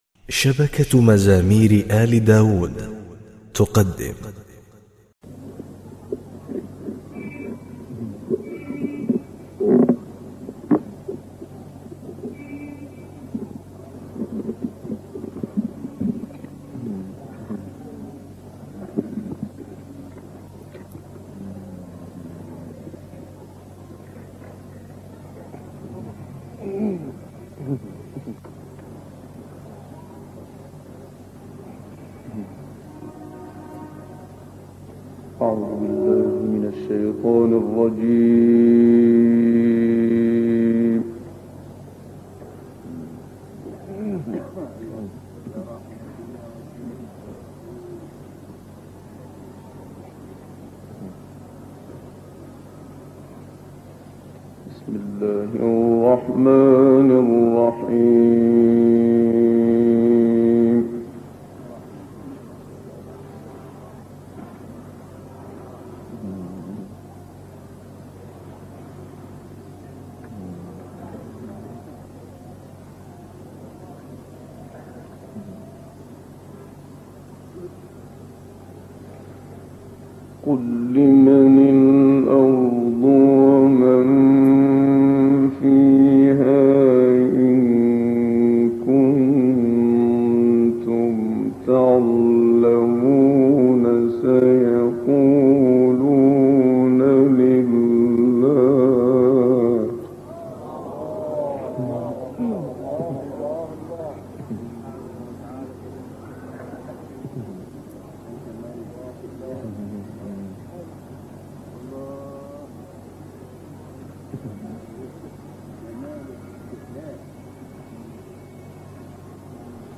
تسجيلات اسيوط - مصر للشيخ محمد صديق المنشاوي